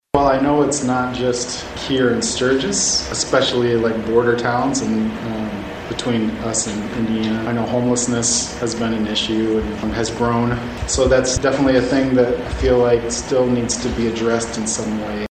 During a Wednesday night work session, the Commission interviewed Justin Wickey for a vacant seat to represent the Second Precinct.